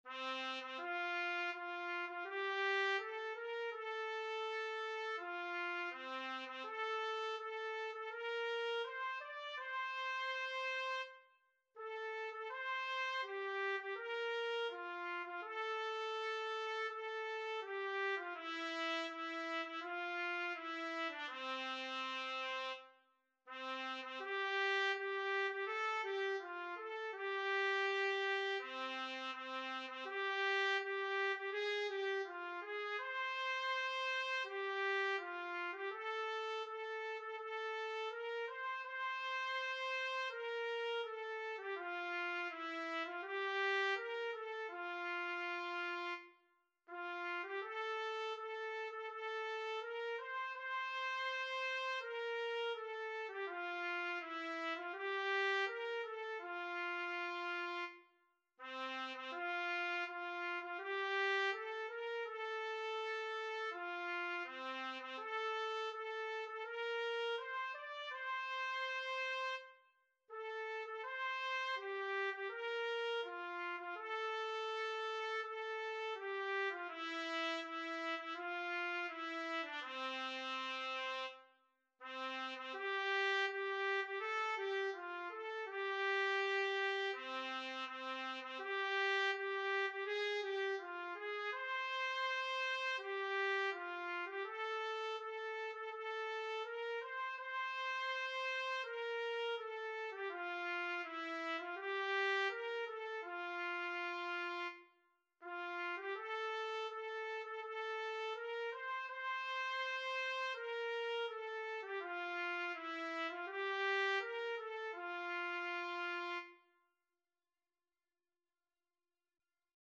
Title: Hino da C.E.I. Composer: F. Tavoni Lyricist: Number of voices: 1v Voicing: Unison Genre: Sacred, Hymn
Language: Portuguese Instruments: Organ